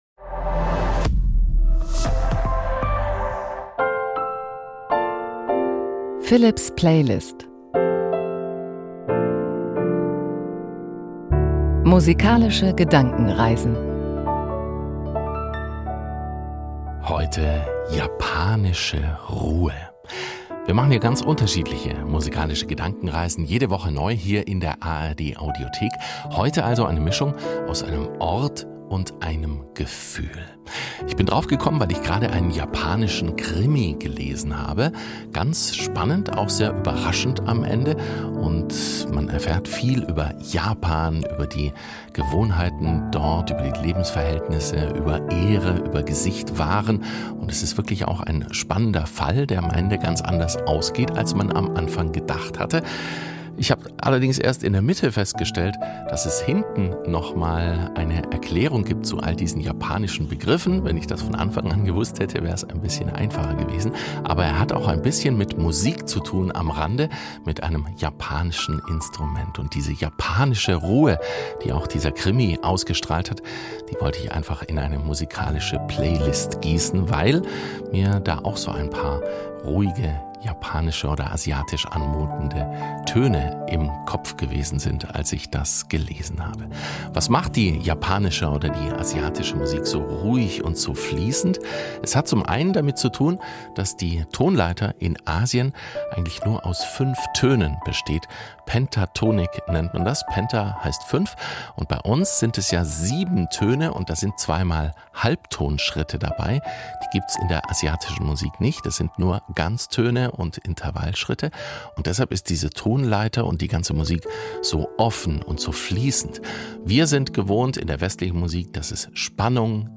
Musik für eine gute Nacht.
Von Pop bis Klassik – die Musik ist für ihn ein Anlass, sich gemeinsam mit seinen Hörerinnen und Hörern auf fantasievolle Gedankenreisen zu begeben. Zum Beispiel auf einen Roadtrip, ans Meer oder in den Weltraum.
Zu den einzelnen Stücken jeder Folge improvisiert er am Klavier.